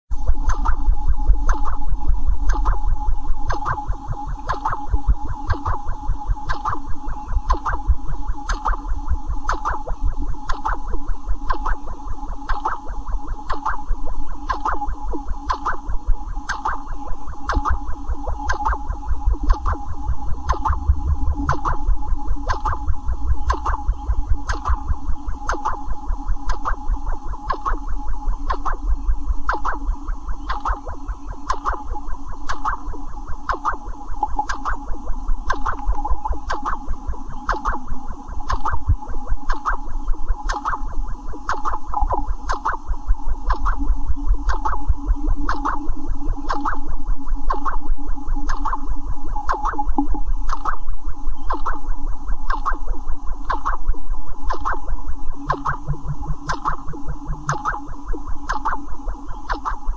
Download Laboratory sound effect for free.
Laboratory